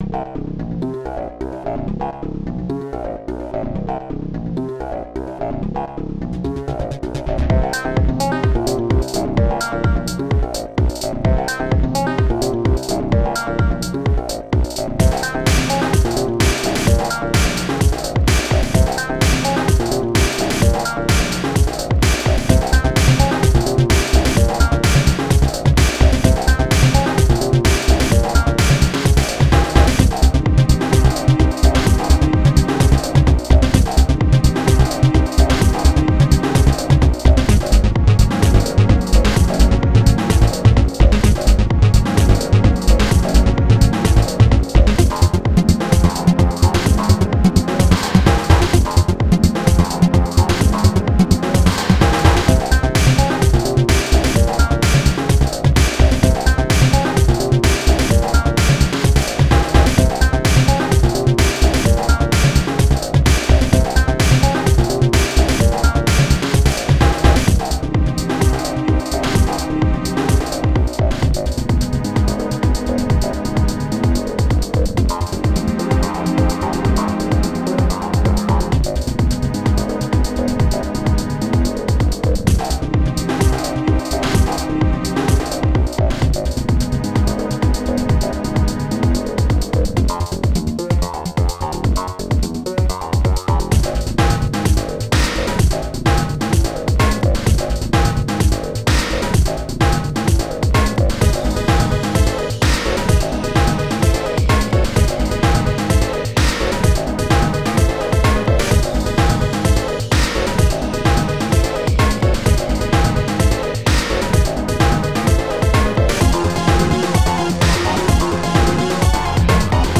2 channels
Tracker